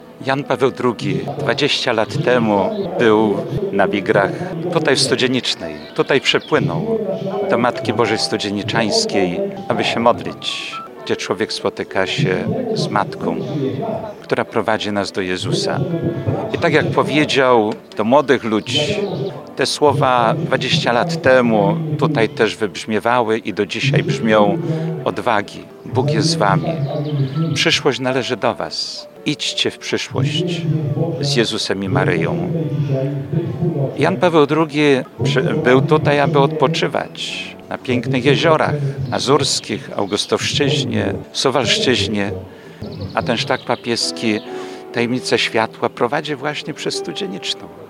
W niedzielę (09.06) 20 lat po tym wydarzeniu, na pamiątkę wizyty Jana Pawła II, biskup ełcki Jerzy Mazur odprawił uroczystą mszę świętą.